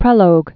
(prĕlōg), Vladimir 1906-1998.